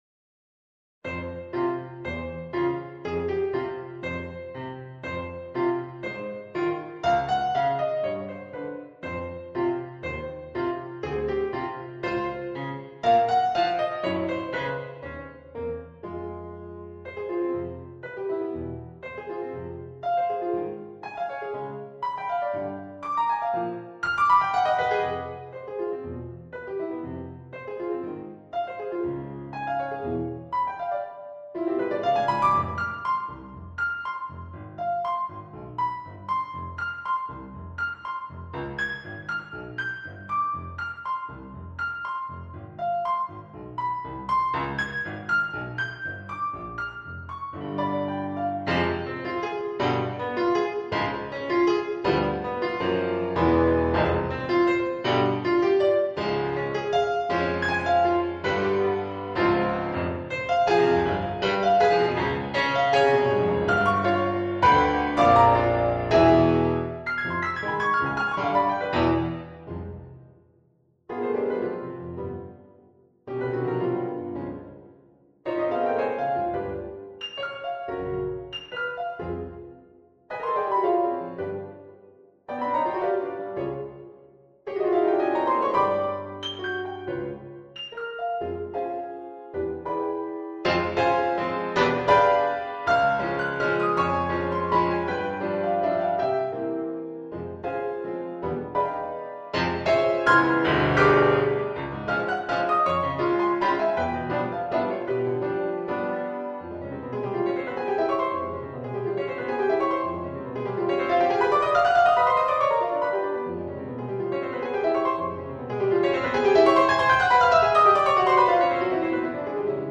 Crosswalk Variations is a series of 24 variations for piano.
With each variation comes not just a different version of the theme, but sometimes even a completely different musical style. Although the piece is in essence a tonal work, it also delves into modality, expanded tonality, 12-tone rows, axis-based symmetry, and other musical devices to create a tapestry of themes and moods.